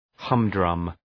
{‘hʌm,drʌm}